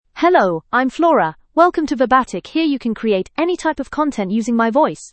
FemaleEnglish (United Kingdom)
Flora is a female AI voice for English (United Kingdom).
Voice sample
Flora delivers clear pronunciation with authentic United Kingdom English intonation, making your content sound professionally produced.